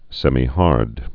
(sĕmē-härd, sĕmī-)